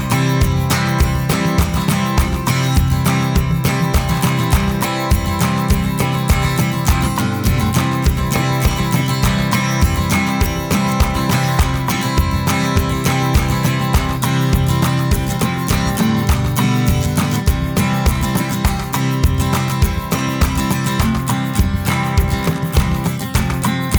No Vocals At All Pop (1960s) 2:45 Buy £1.50